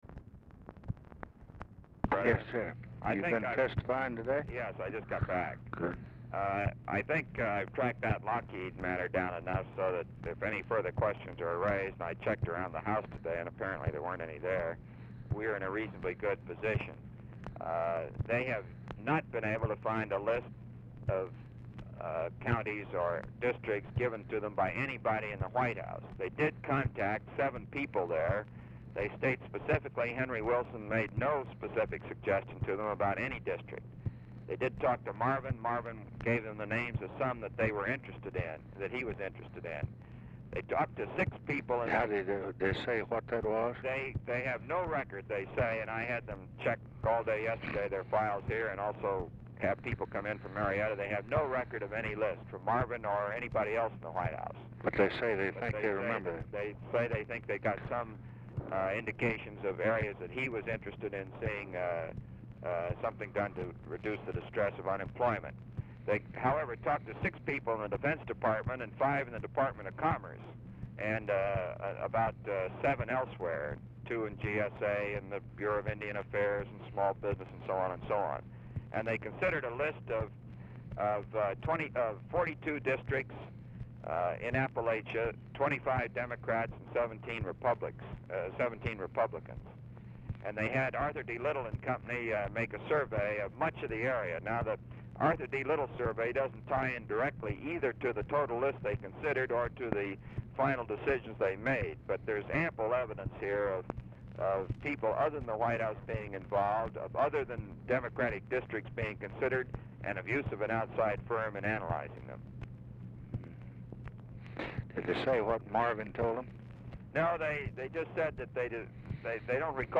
Telephone conversation # 11559, sound recording, LBJ and ROBERT MCNAMARA, 2/20/1967, 5:20PM | Discover LBJ
Format Dictation belt
Location Of Speaker 1 Mansion, White House, Washington, DC
Specific Item Type Telephone conversation